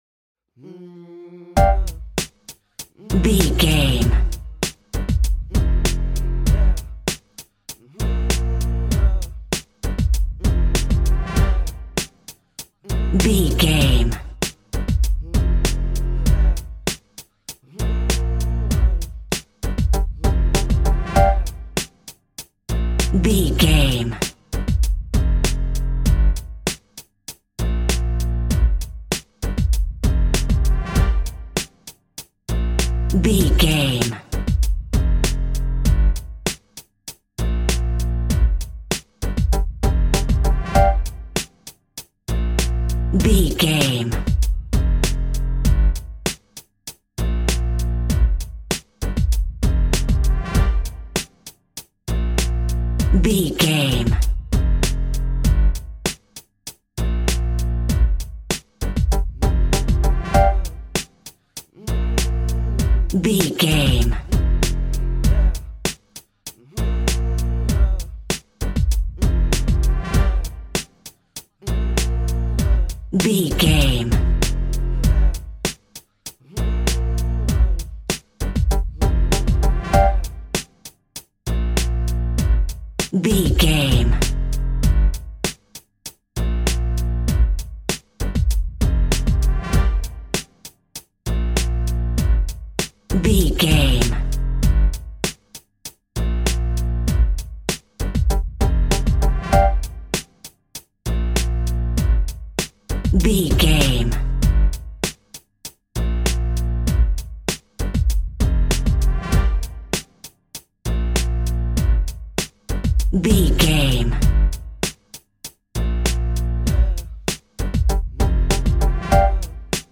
Aeolian/Minor
Funk
hip hop
electronic
drum machine
synths